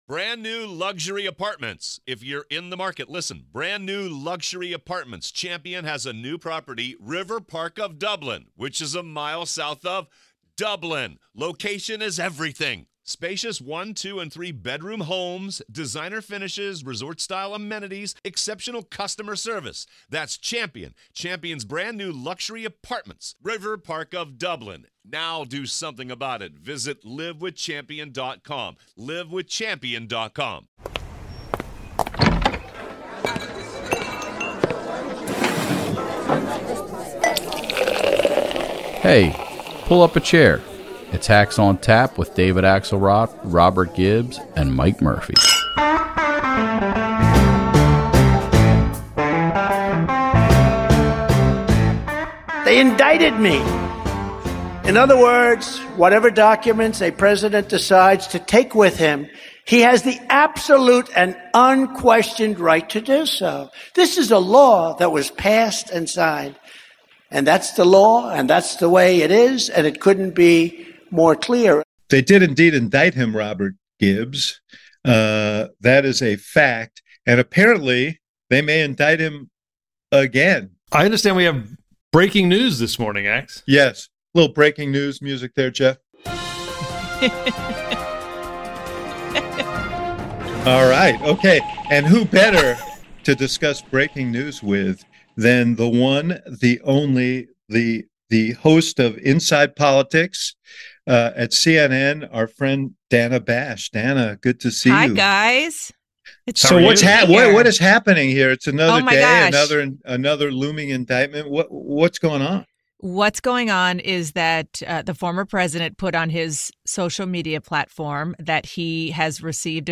This bonkers news week Axe and Gibbs were joined by CNN’s chief political correspondent and anchor of “Inside Politics”. Dana walks us through the newest Trump indictment news, and the Hacks pick her brain on Trump’s messaging, what the indictments mean for 2024, and how the A...